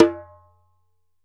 ASHIKO 4 06R.wav